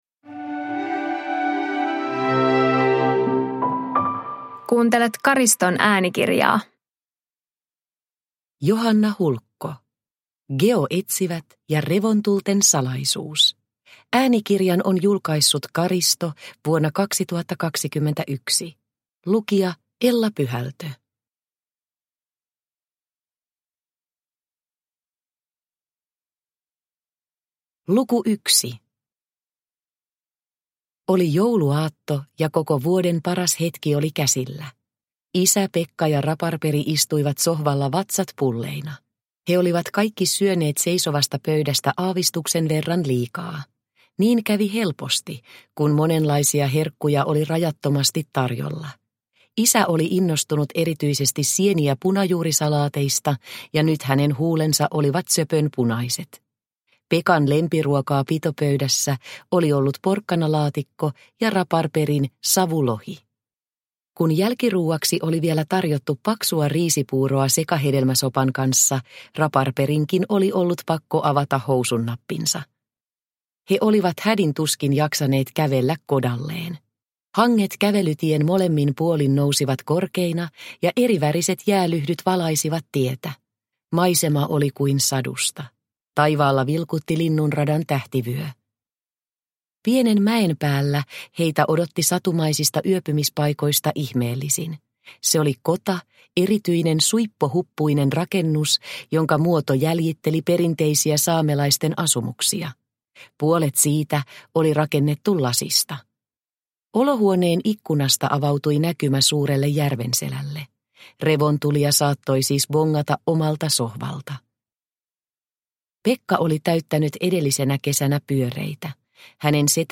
Geoetsivät ja revontulten salaisuus – Ljudbok – Laddas ner